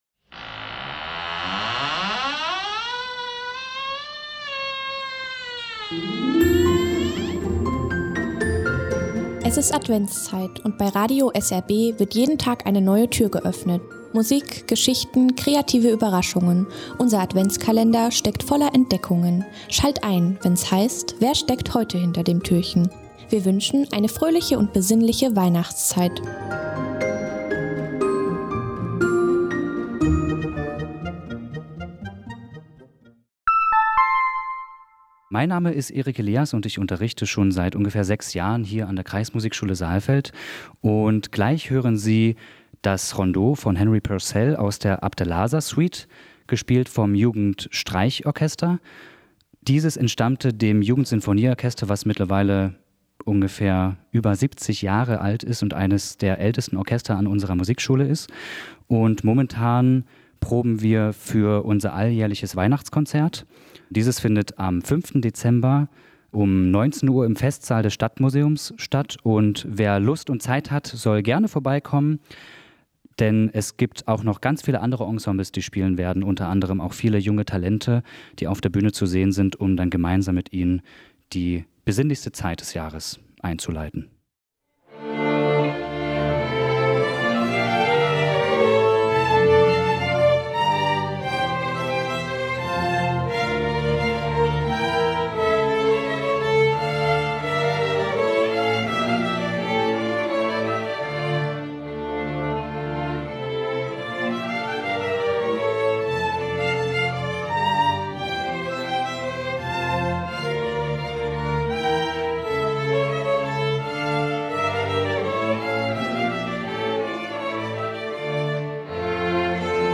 Das Streichorchester der Kreismusikschule Saalfeld